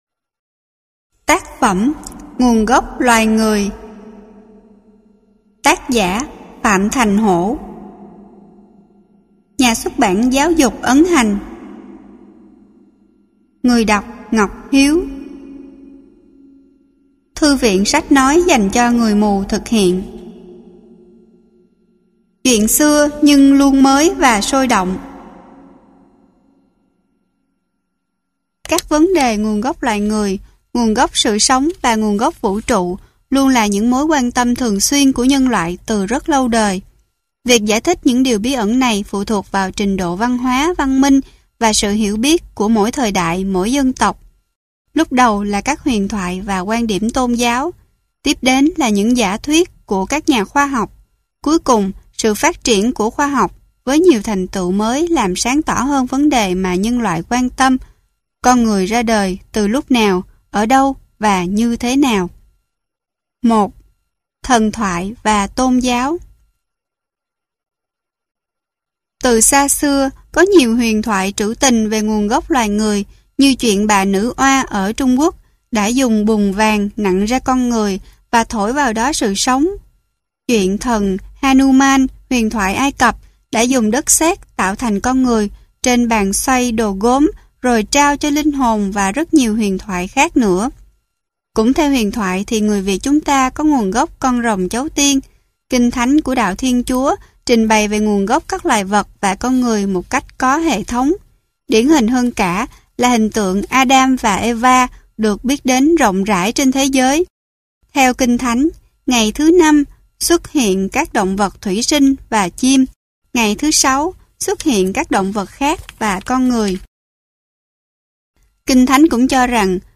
Sách nói Nguồn Gốc Loài Người - Phạm Thành Hổ - Sách Nói Online Hay